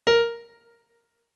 MIDI-Synthesizer/Project/Piano/50.ogg at 51c16a17ac42a0203ee77c8c68e83996ce3f6132